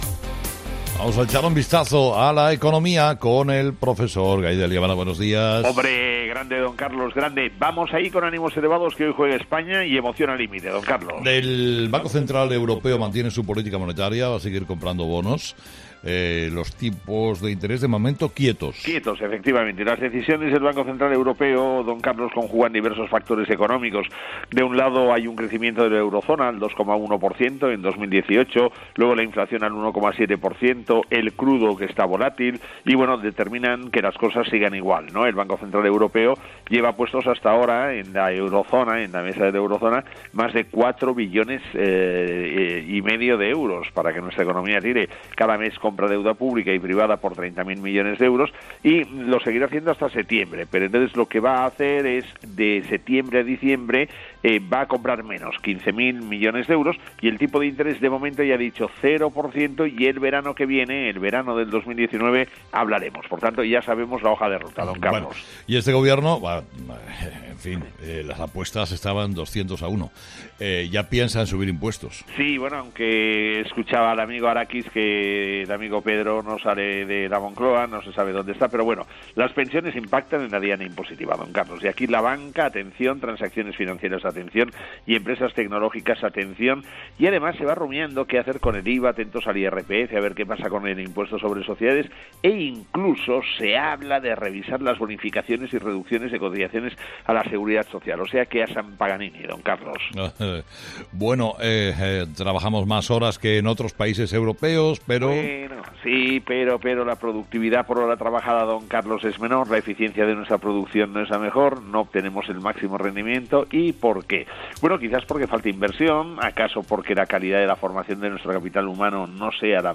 Escucha ahora: La primera mirada económica del día con el profesor Gay de Liébana en ‘Herrera en COPE’.